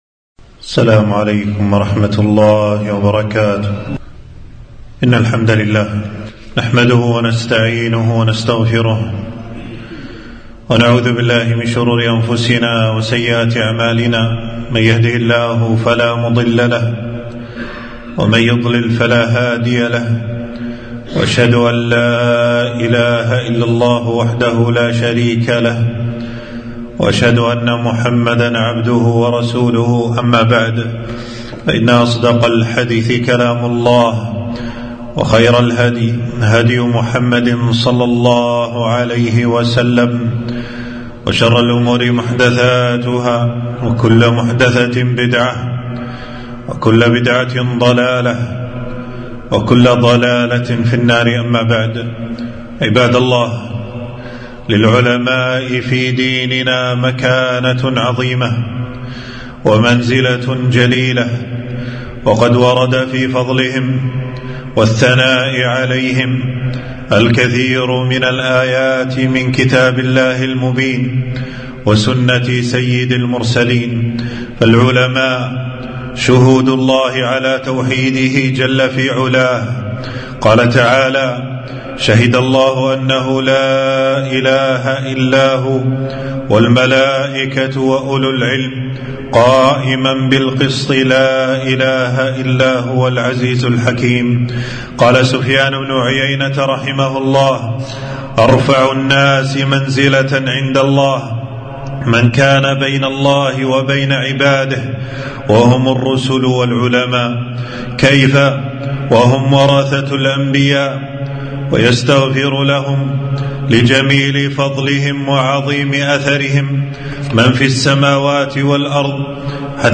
خطبة - موت العالم ثُلمة في الإسلام